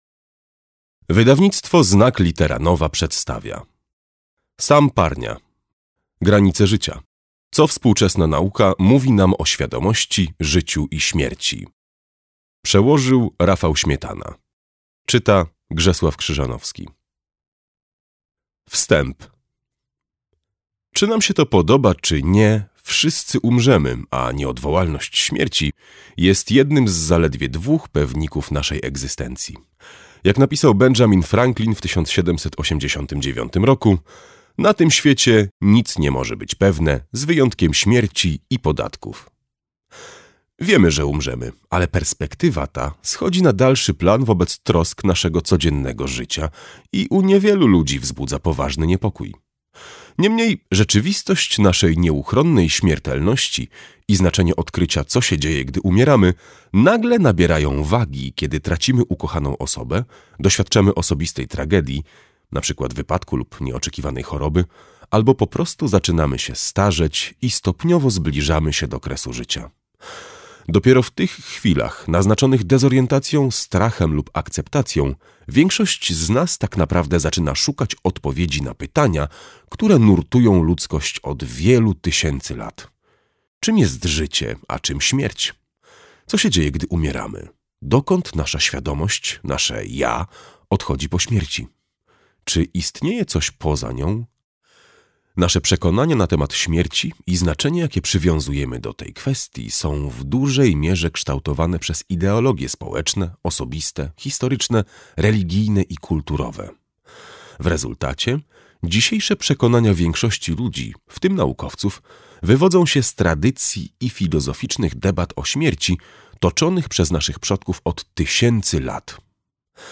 Granice życia. Co współczesna nauka mówi nam o świadomości, życiu i śmierci - Parnia Sam - audiobook